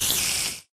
mob / spider1